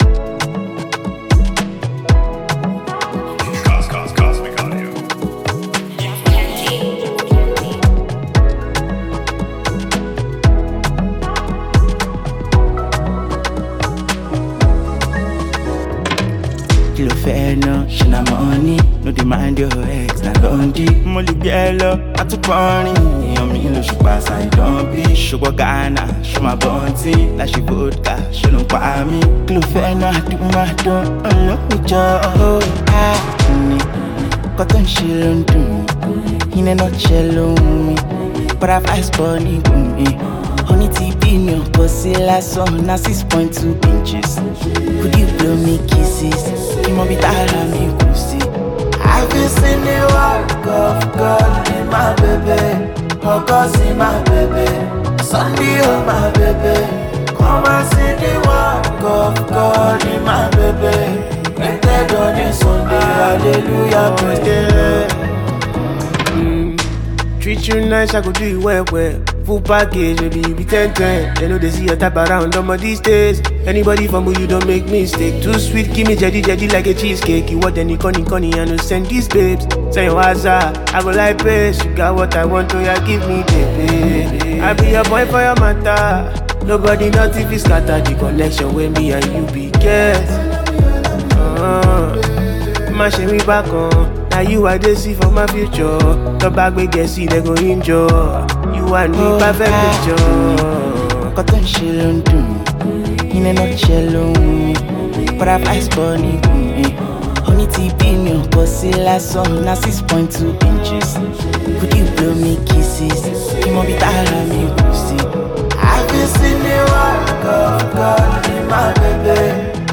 Afropop
smooth Afrobeat-inspired love song
blends heartfelt lyrics with captivating melodies